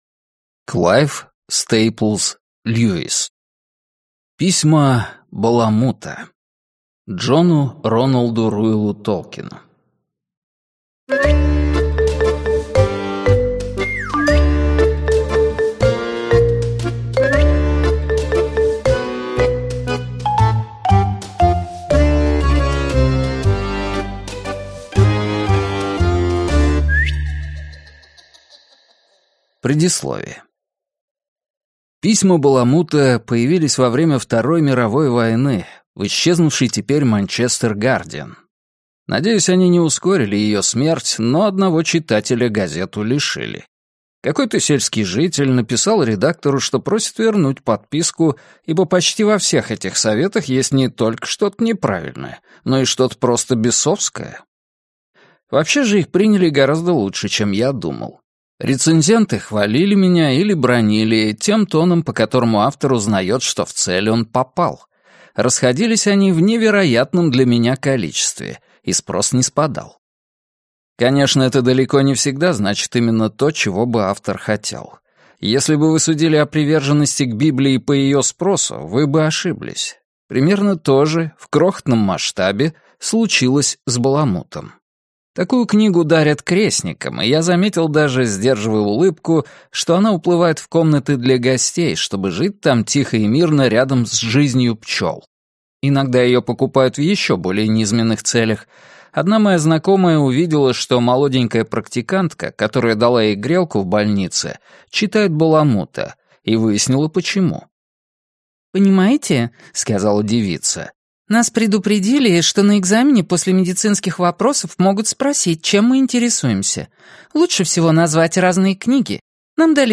Аудиокнига Письма Баламута. Расторжение брака | Библиотека аудиокниг